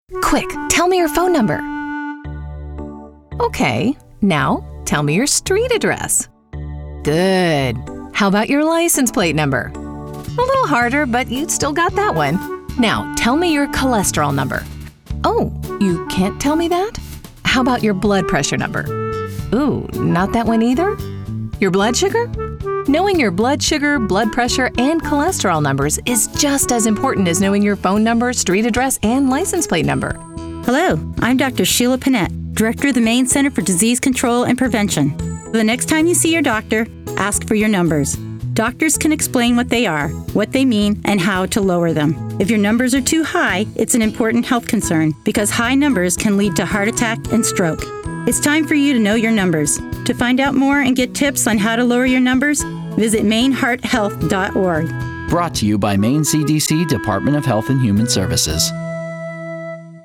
Know Your Numbers Radio Spot
MeCDC_Numbers_Radio_Talent_&_Dr_Mix01.mp3